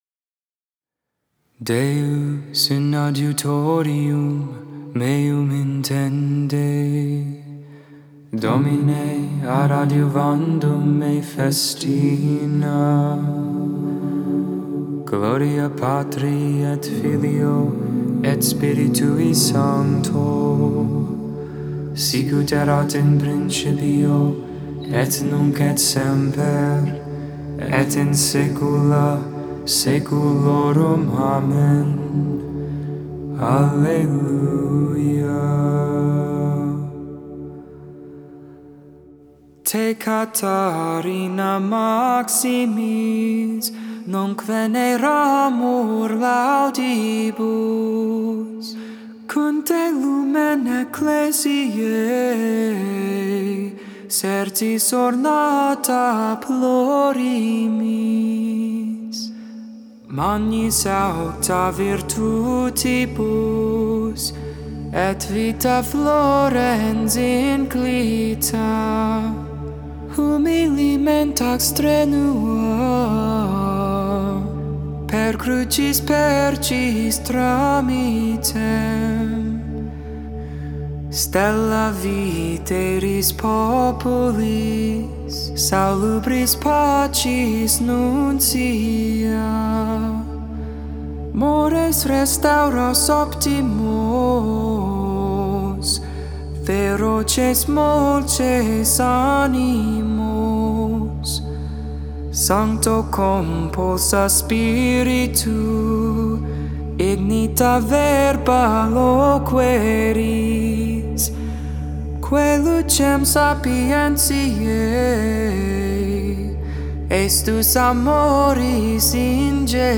4.29.21 Lauds, Thursday Morning Prayer